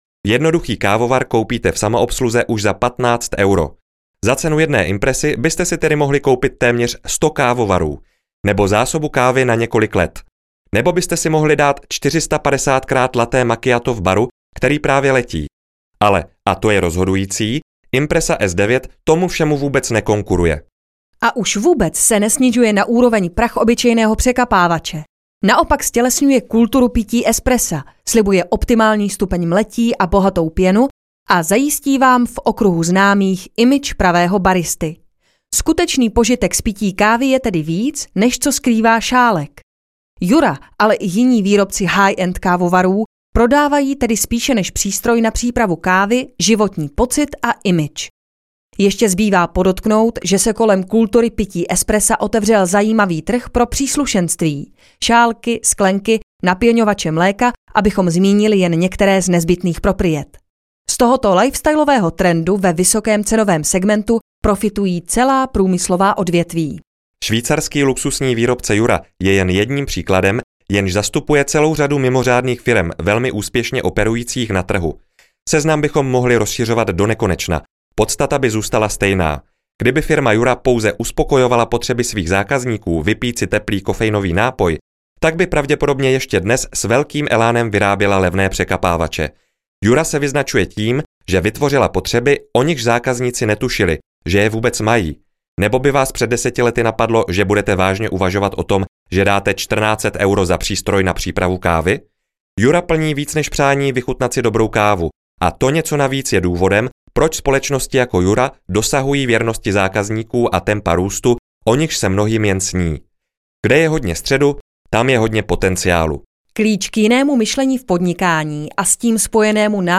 Audioknihy